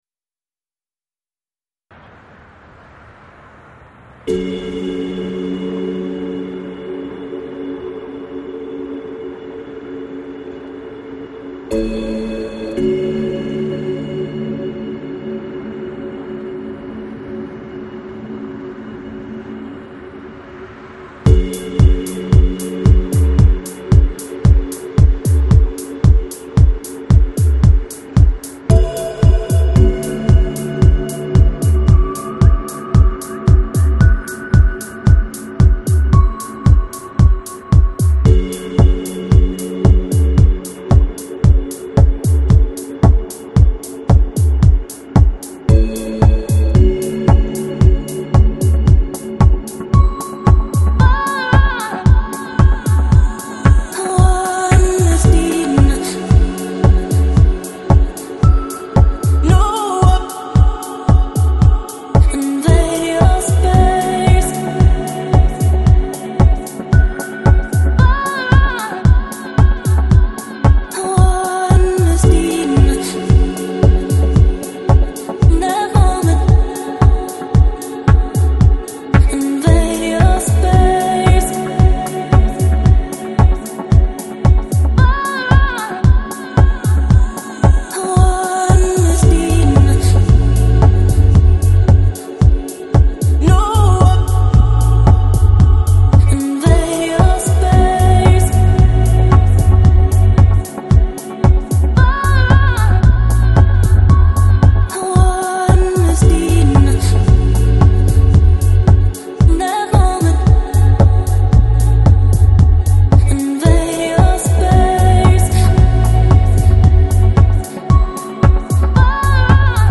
Lounge, Downtempo, Chill House, Deep House